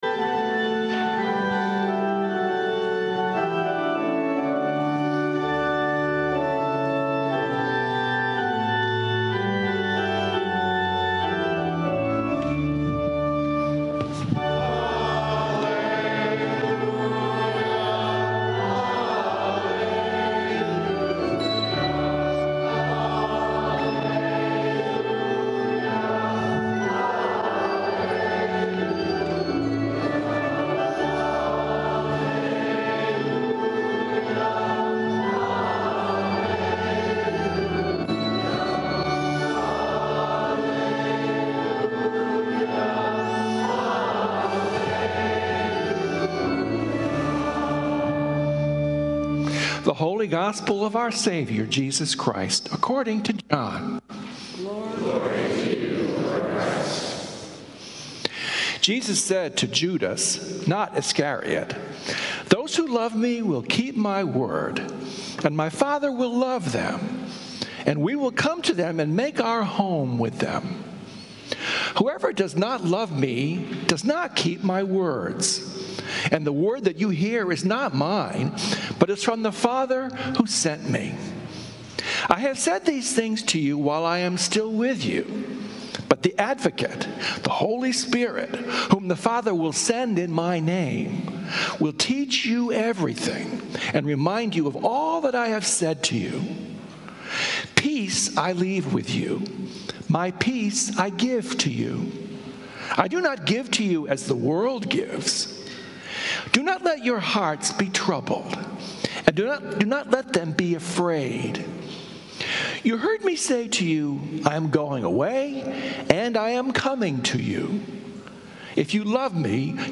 Sermons from St. Columba's in Washington, D.C. Sunday Sermon